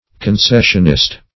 Concessionist \Con*ces"sion*ist\, n. One who favors concession.